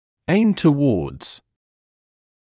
[eɪmd tə’wɔːdz][эймд тэ’уоːдз]направленный на